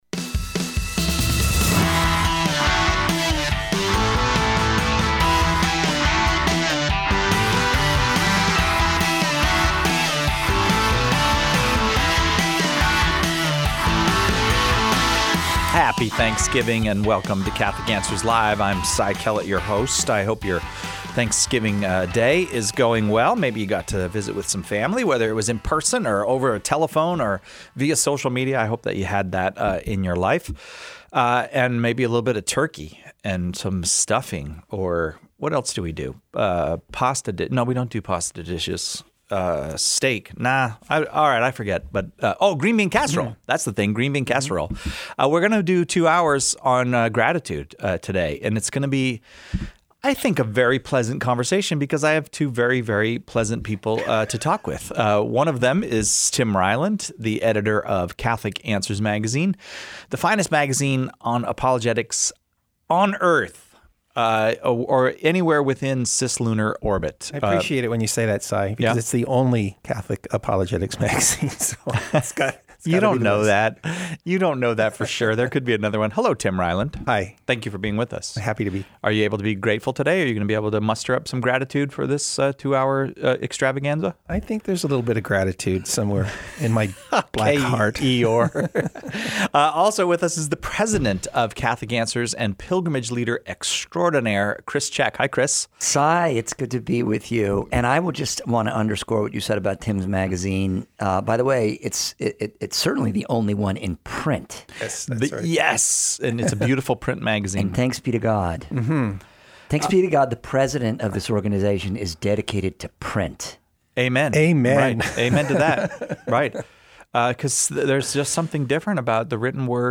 A lighthearted conversation